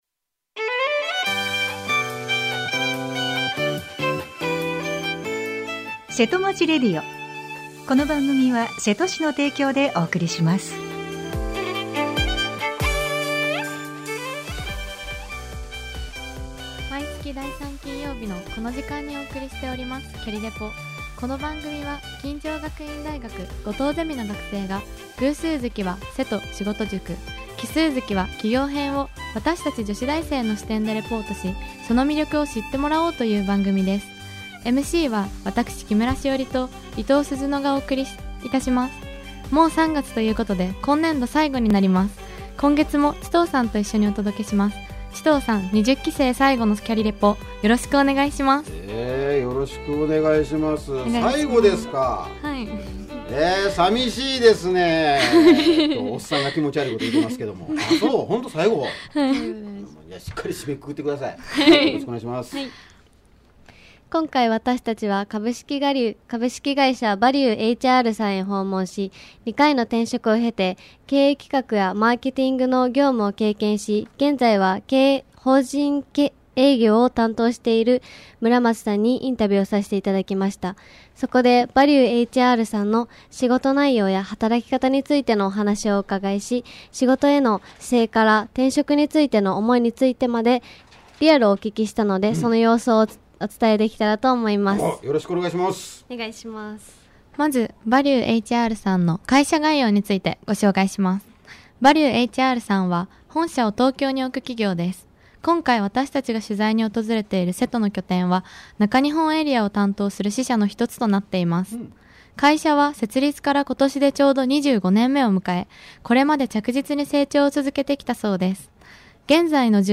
奇数月は「瀬戸の企業」を女子大生の視点でレポートし、 その魅力を知ってもらおうという番組です。